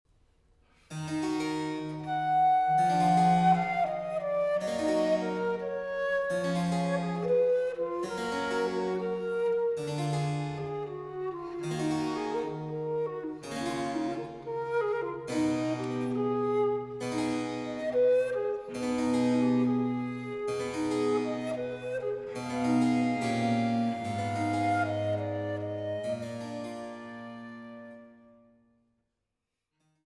Traversflöte
Air